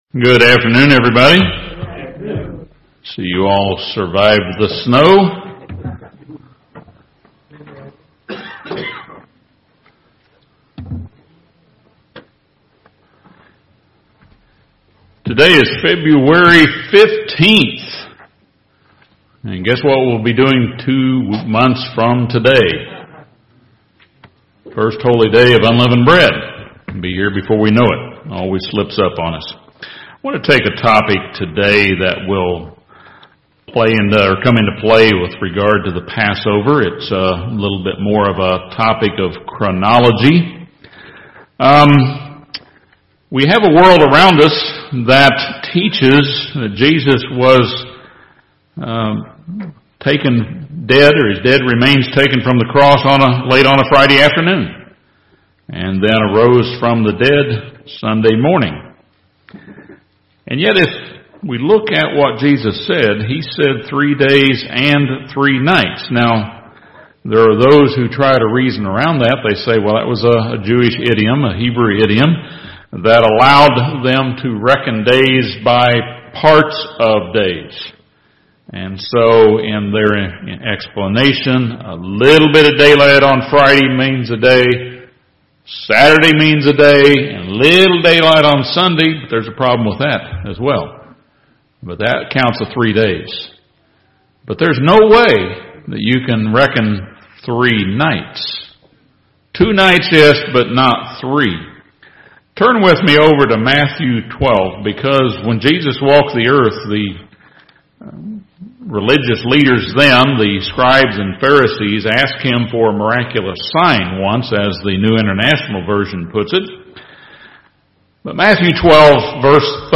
This sermon explains the Biblical clues that lead us to teach that Christ was literally in His grave for 72 hours.